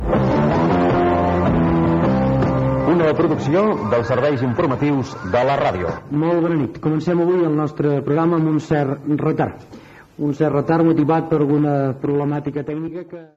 Final de la careta i presentació inicial amb disculpes pel retard en l'inici del programa
Informatiu